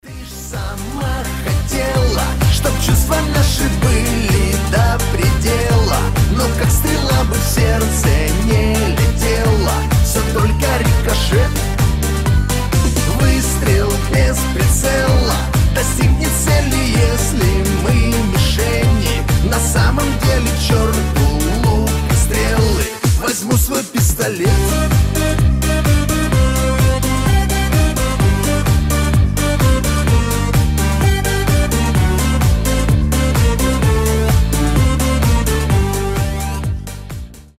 • Качество: 320, Stereo
мужской голос
веселые
блатные